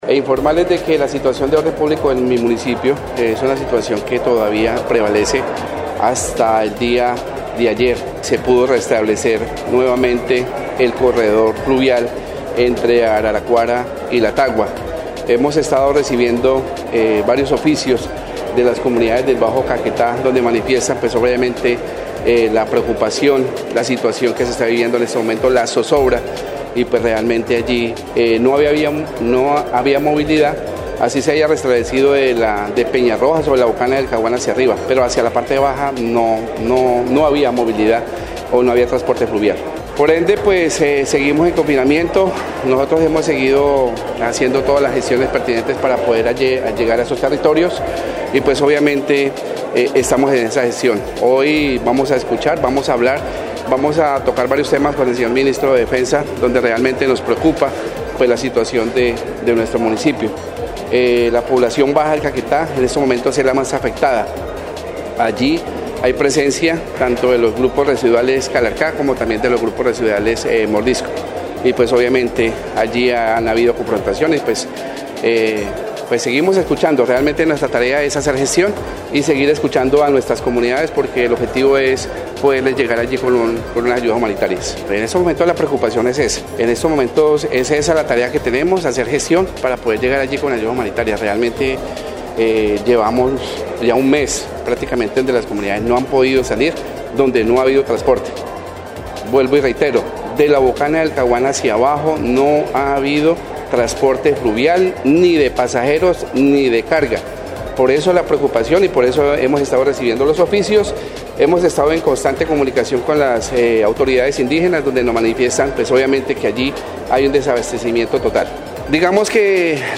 02_ALCALDE_LUIS_GONZALES_CONFINAMIENTO.mp3